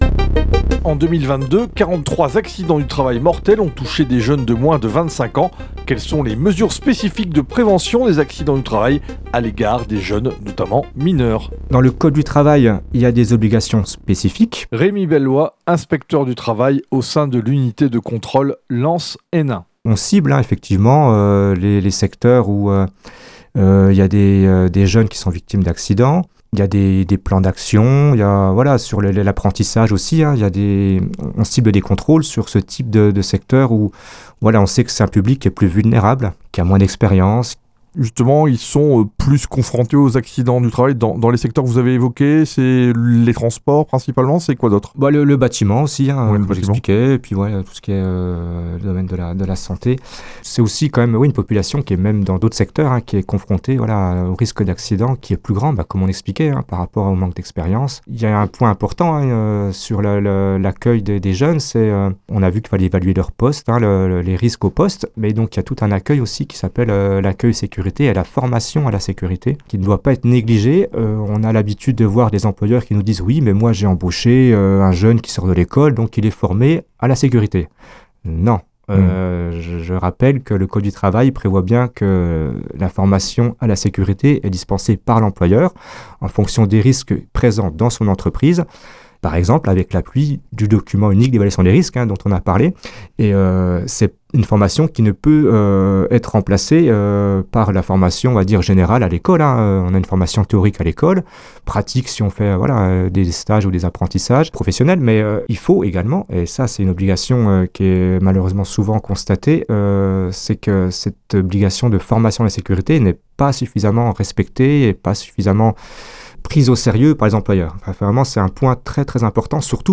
8 spots radio diffusés à l’automne 2025 :